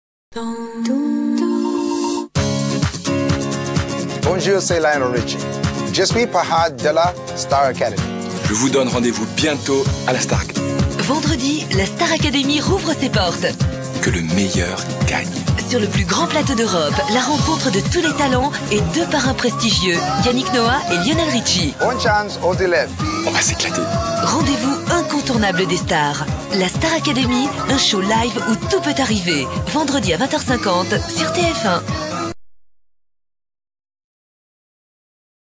Promo | STAR ACADEMY